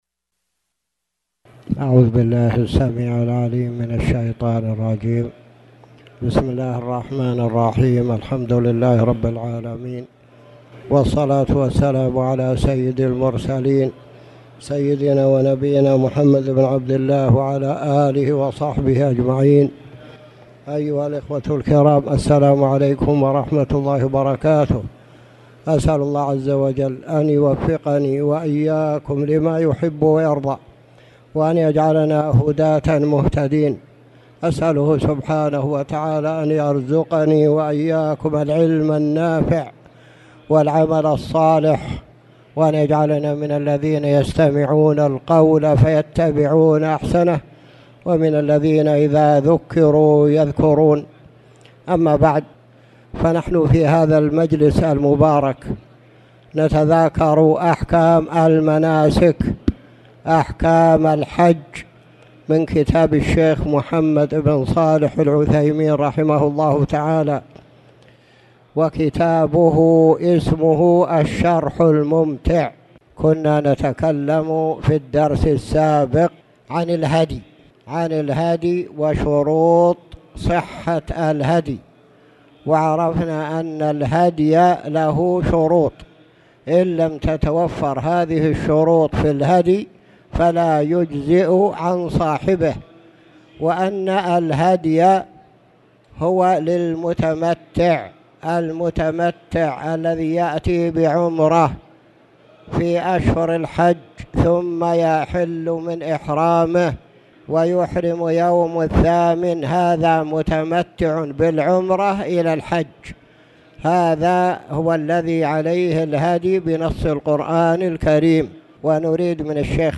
تاريخ النشر ١٦ ذو القعدة ١٤٣٨ هـ المكان: المسجد الحرام الشيخ